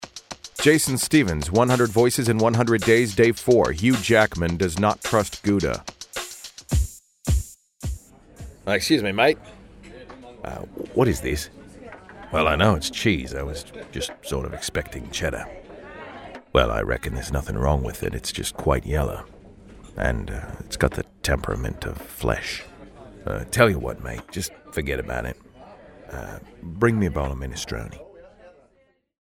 I don’t have a lot of mileage on my Hugh Jackman impression, and the accuracy varies based on the tone he takes.
Categories: 100 Voices in 100 Days, News
Tags: Hugh Jackman impression